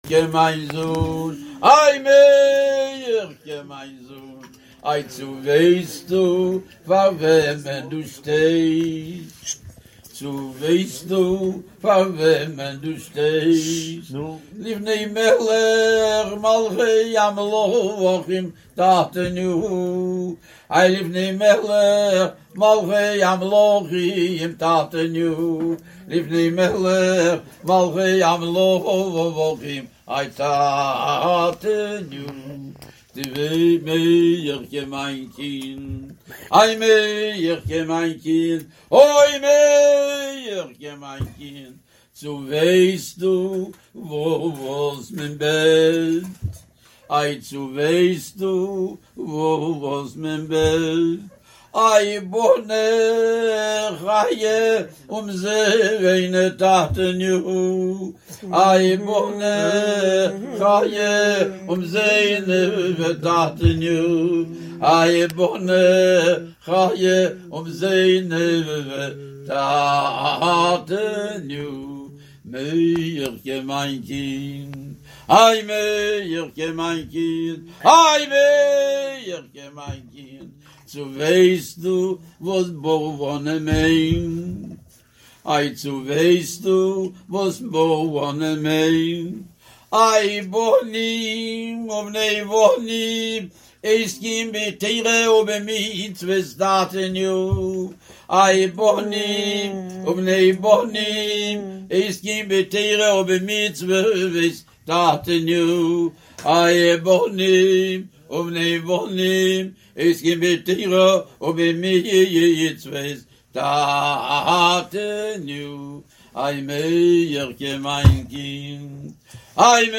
ניגוני דביקות וכיספים
ניגוני חבד